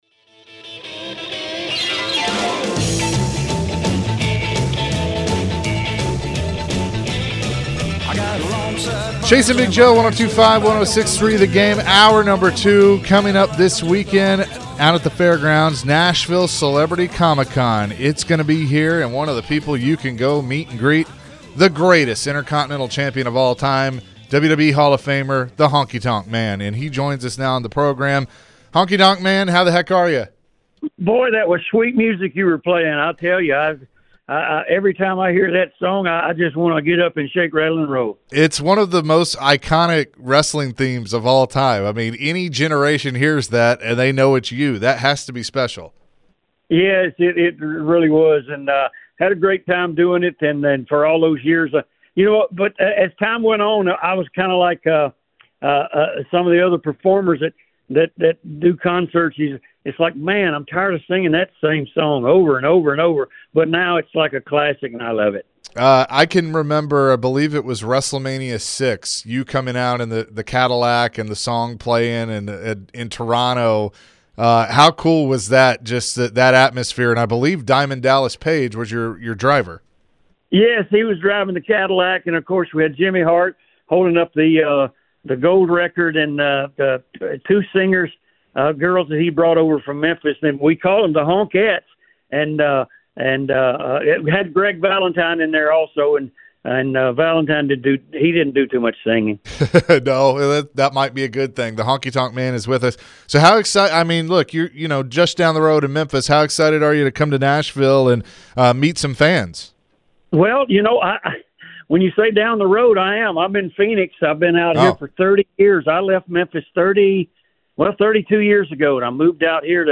WWE Hall of Famer Honky Tonk Man joined the show as he will be coming into town for the Nashville Comic Con. Honky Tonk man went down memory lane and discussed how the business was back in the day.